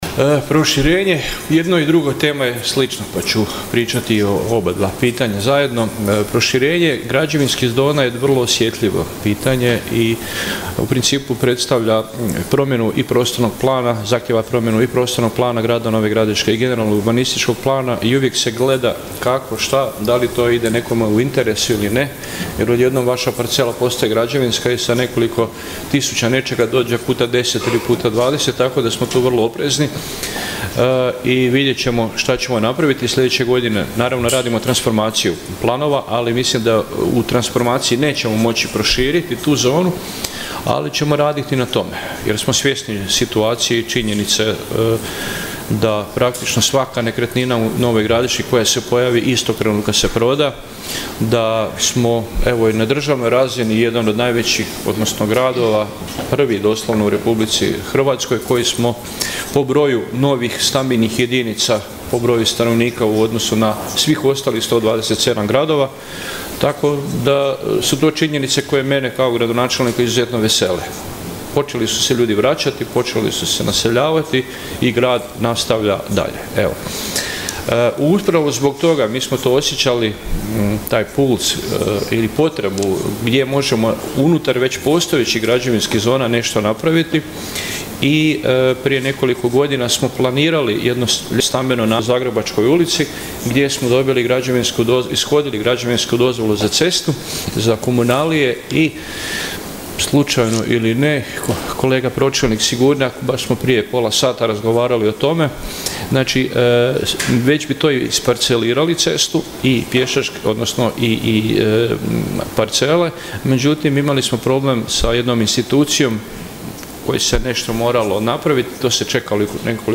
Aktualni sat 6. sjednice Gradskog vijeća Grada Nova Gradiška i ovoga je puta bio prilika gradskim vijećnicima da postave pitanja vezana uz svakodnevno funkcioniranje grada i rad gradske uprave. Na sva postavljena pitanja odgovarao je gradonačelnik Vinko Grgić.